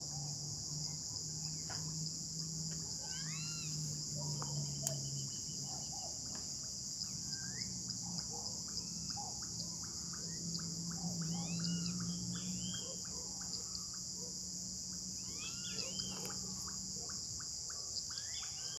Rufous-bellied Thrush (Turdus rufiventris)
Location or protected area: Concordia
Condition: Wild
Certainty: Observed, Recorded vocal